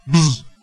蜜蜂2
描述：声音嗡嗡声
Tag: 蜜蜂 嗡嗡声 语音